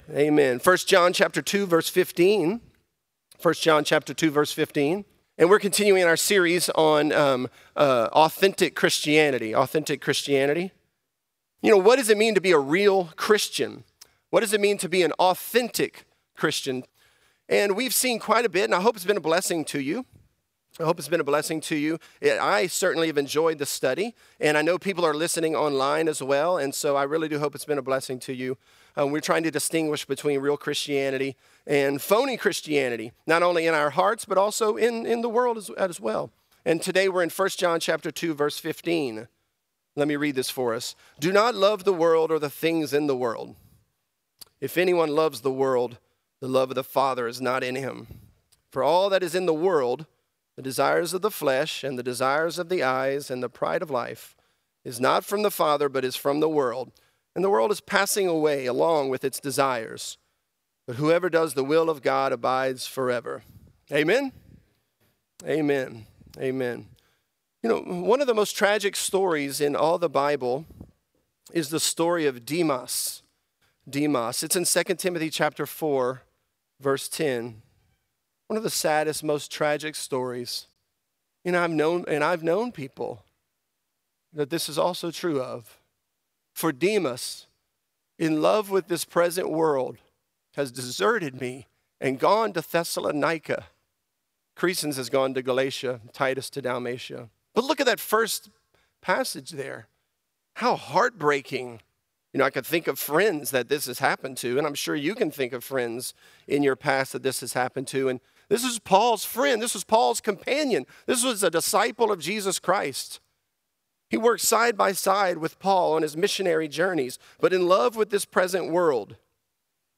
Authentic: The Love of The Father | Lafayette - Sermon (1 John 2)